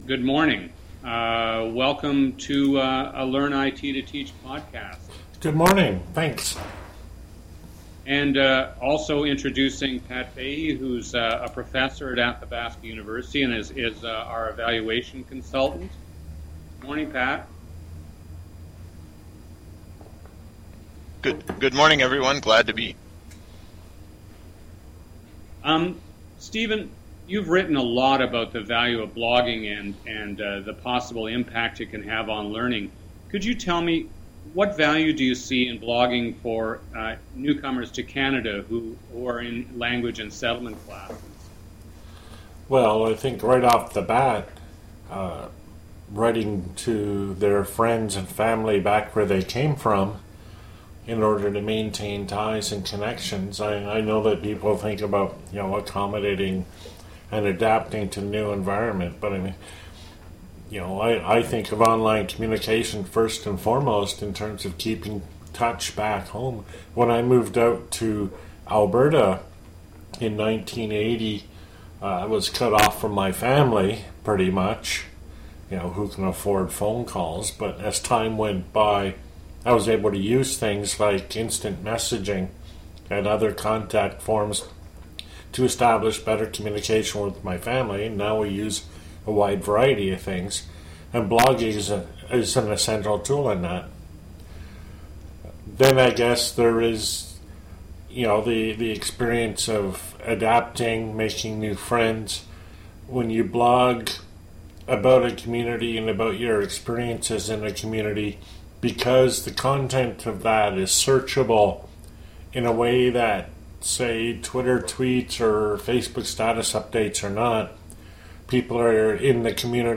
Podcast interview focusing on communications and blogging in online learning.